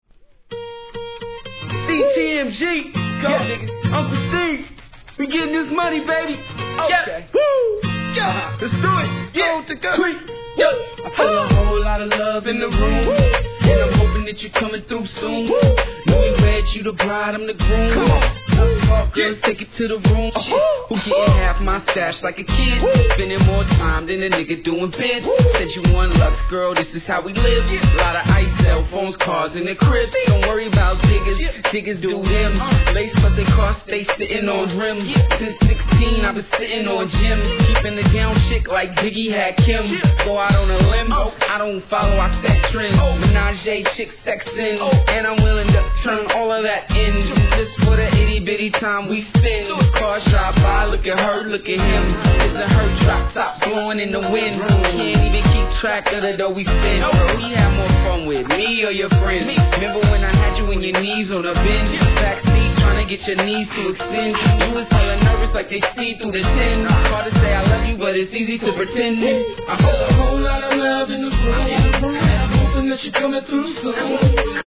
HIP HOP/R&B
哀愁系のスパニッシュ・メロディーに当時流行のクラップ音は王道でした!!